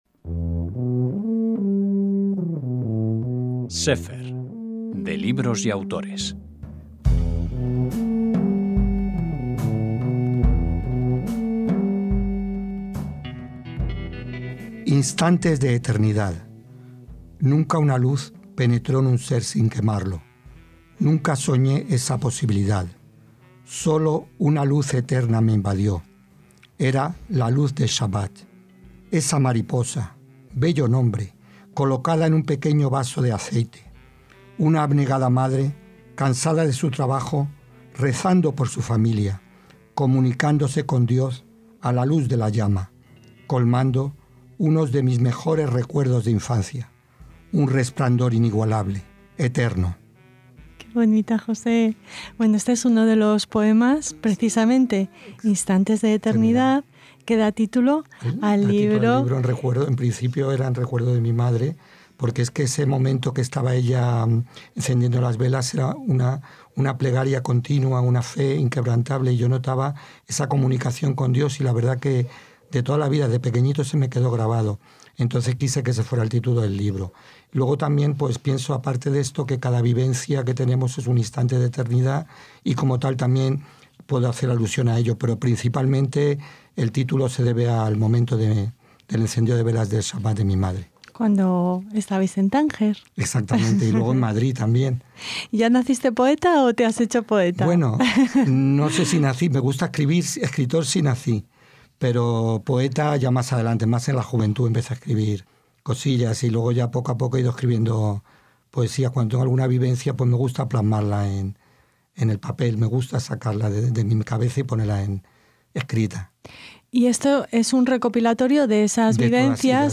Lee para nosotros algunos de estos poemas íntimos, de amor, de pertenencia, de denuncia, de recuerdos del Shabat en su Tánger de infancia.